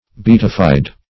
beatified \be*at"i*fied\ (b[-e]*[a^]t"[i^]*f[imac]d), adj.